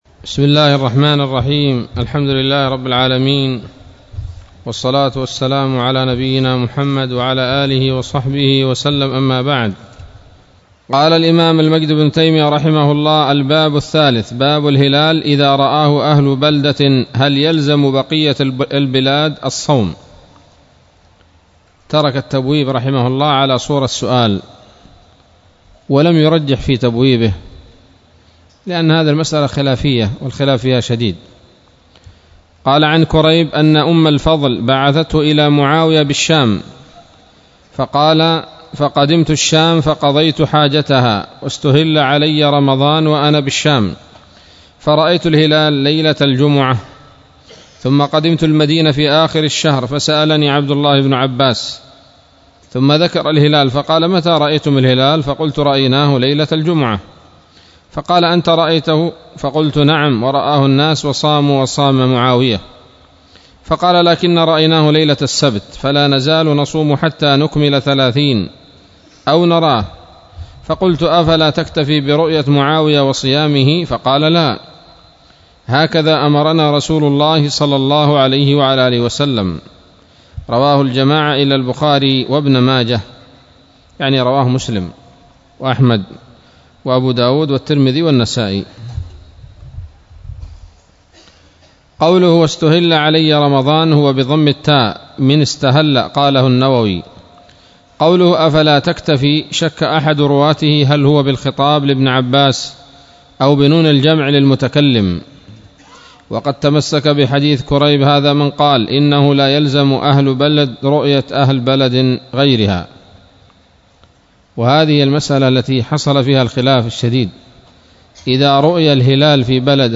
الدرس السادس من كتاب الصيام من نيل الأوطار